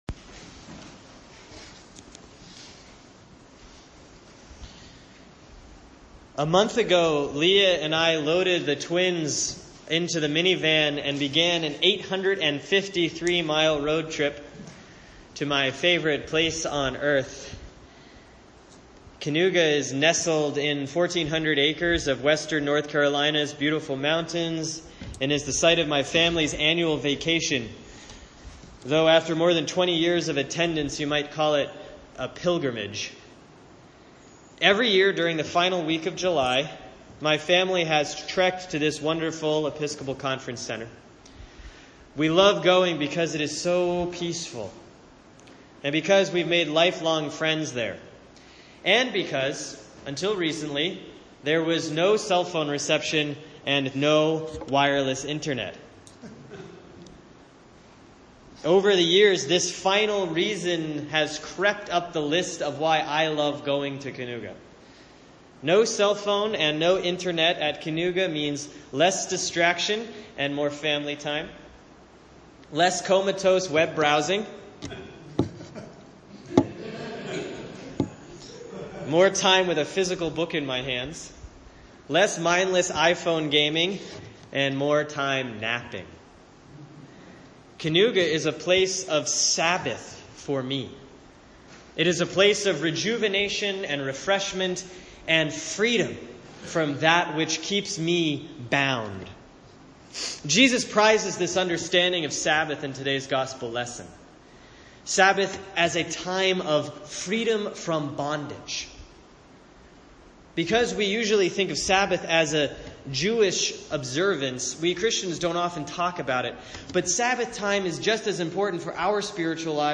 Sermon for Sunday, August 21, 2016 || Proper 16C || Luke 13:10-17